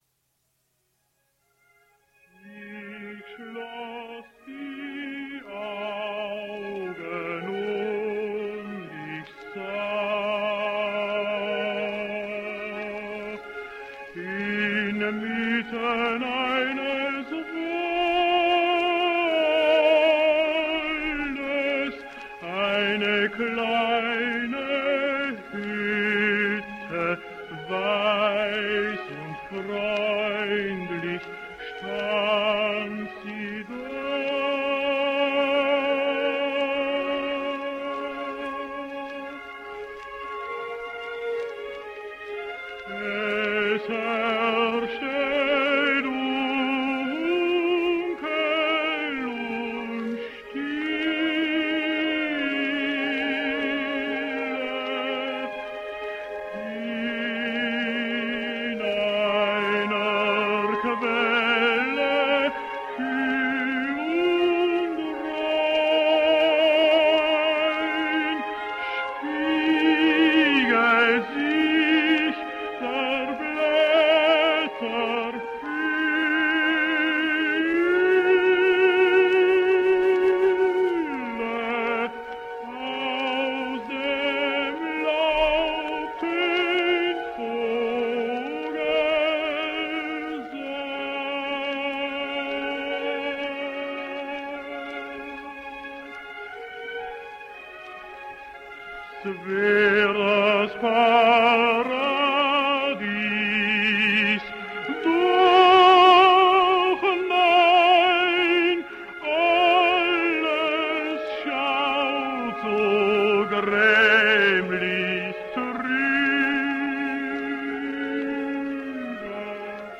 Austrian tenor.
Both Patzak and Erb were noted for the silvery timbre of their voices.